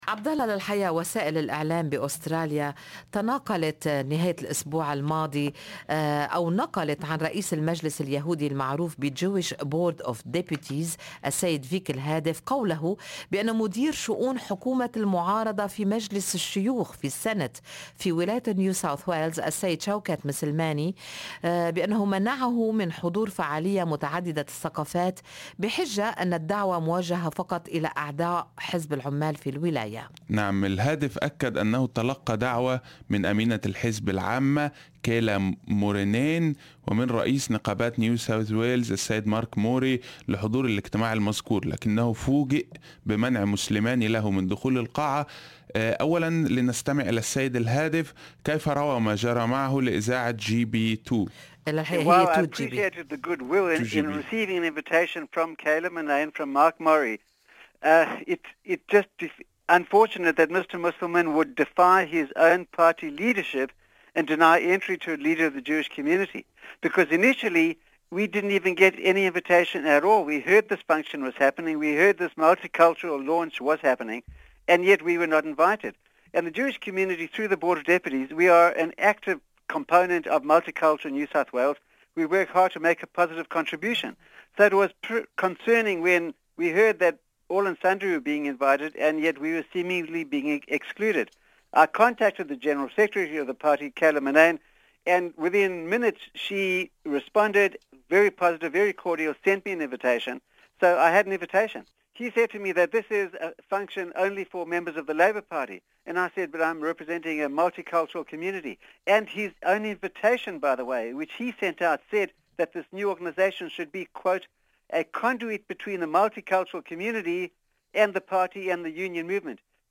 Retired Labor MP Shaoquett Moselmane Source: Supplied
شوكت مسلماني، وهو أول مسلم يُنتخب في برلمان نيو ساوث ويلز قال في مقابلة مع برنامج "صباح الخير أستراليا" على راديو أس بي أس عربي24 أن الاجتماع المقصود كان اجتماعا لأحد لجان حزب العمال وليس ملتقى للجاليات من خلفيات ثقافية مختلفة.